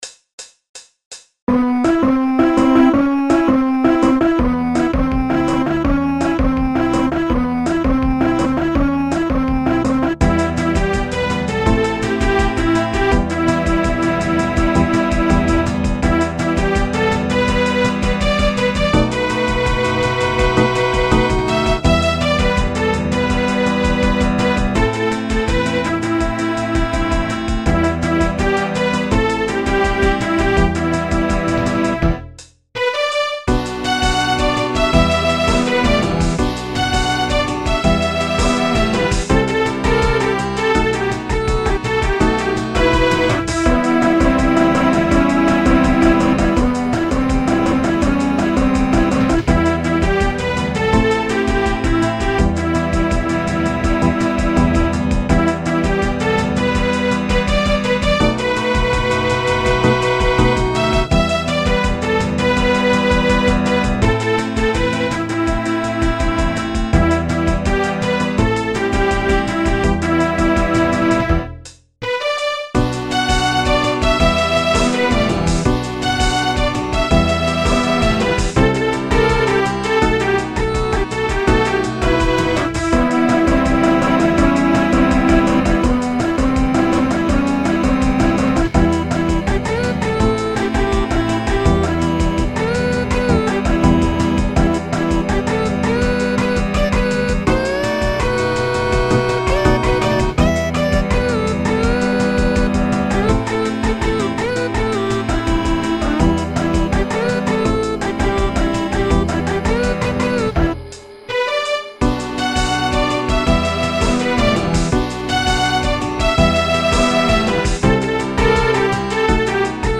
snes soundfont 16-bit